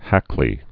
(hăklē)